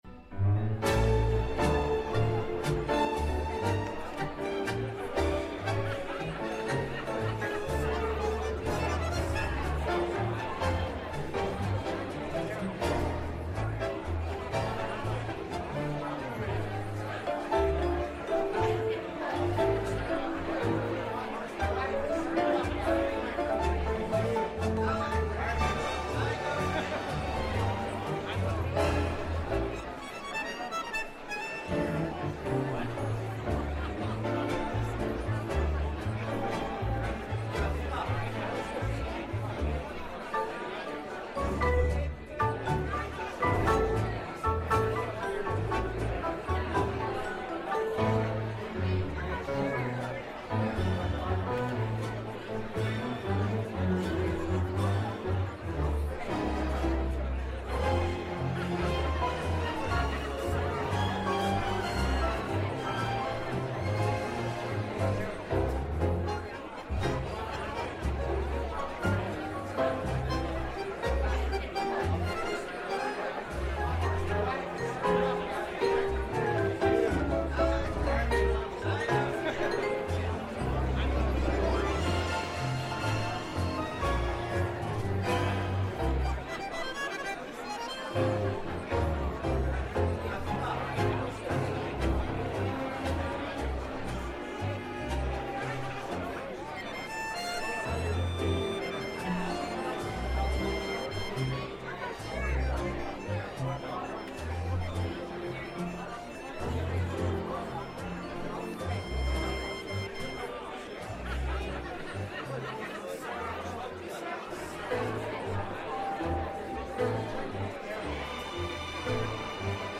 tangos-madrid10.mp3